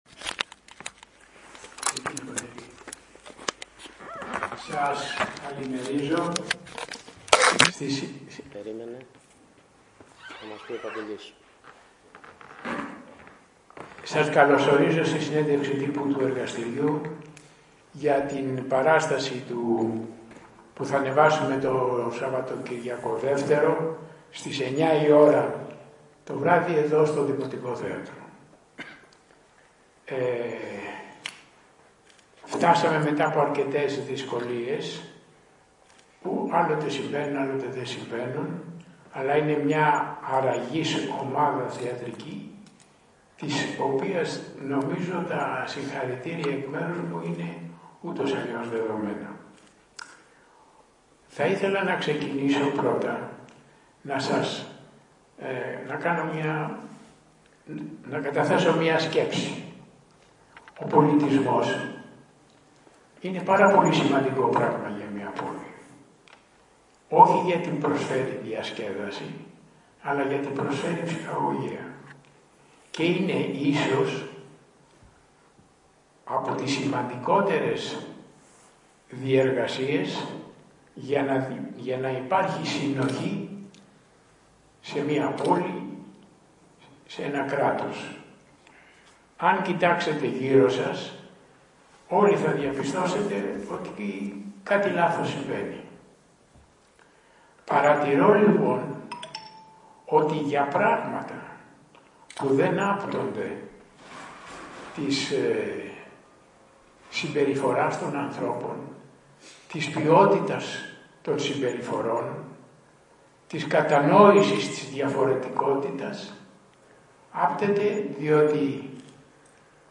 Συνέντευξη από το Θεατρικό Εργαστήρι Σύγχρονης Τέχνης Κεφαλονιάς για το νέο έργο που ανεβάζει στο Δημοτικό Θέατρο “Ο ΚΕΦΑΛΟΣ” .